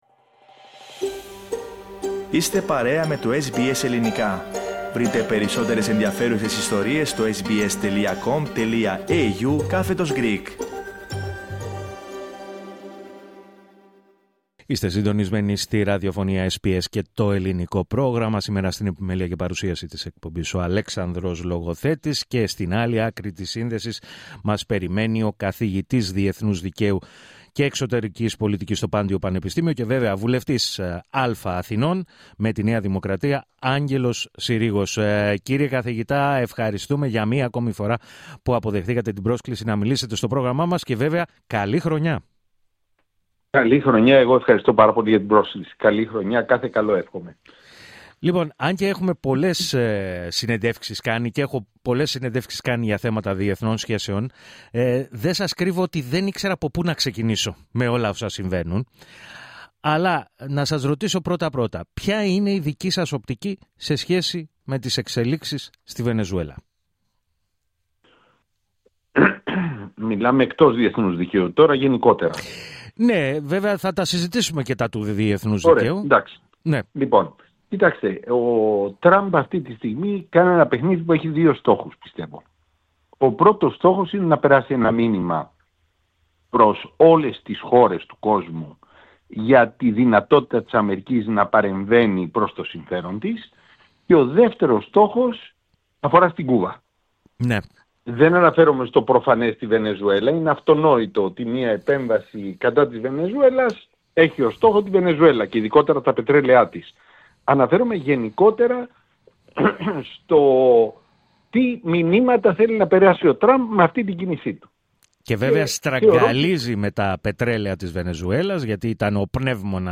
Στον απόηχο της απαγωγής του Νικολάς Μαδούρο από τις ΗΠΑ, μίλησε στο Ελληνικό Πρόγραμμα της ραδιοφωνίας SBS, ο καθηγητής Διεθνούς Δικαίου, στο Πάντειο Πανεπιστήμιο και βουλευτής Α’ Αθηνών, με τη ΝΔ, Άγγελος Συρίγος.